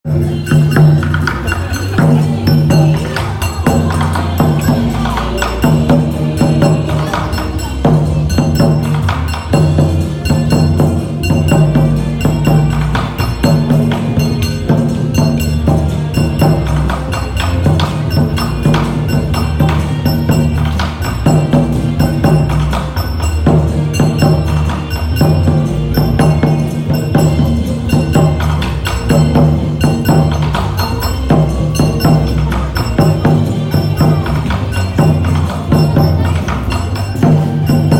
夜、妻と散歩に行くと、路上がお祭りゾーンになっていた。
大きいところは、ヤグラの上で太鼓をたたきながら周りで踊り、脇で宴会を繰り広げていた。
（おまけの種）祭りの音